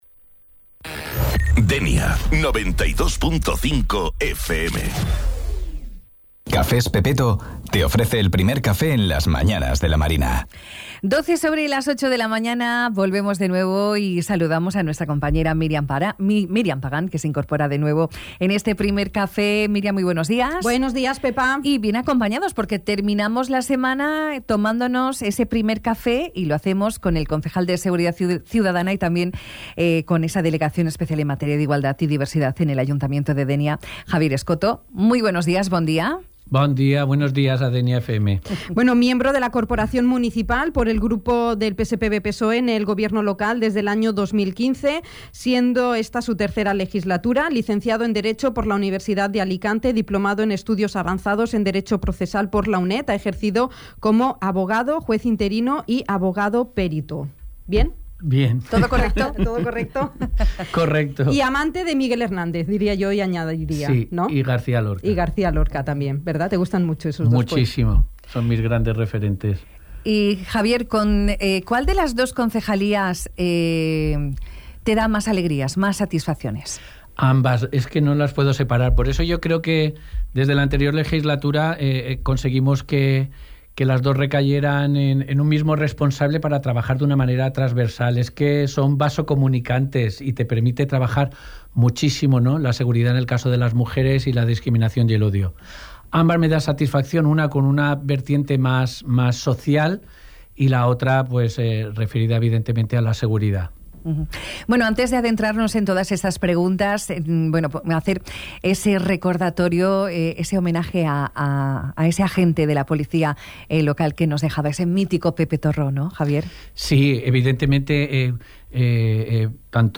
El concejal de Seguridad Ciudadana y de Igualdad y Diversidad en el Ayuntamiento de Dénia, Javier Scotto ha visitado el ‘primer café’, de Dénia FM. Sobre la mesa diferentes aspectos de la seguridad viaria en el municipio, tales como los cruces del TRAM a su paso por la ciudad, el proyecto del transporte público o la implementación de cámaras de seguridad.
Entrevista-Javier-Scotto.mp3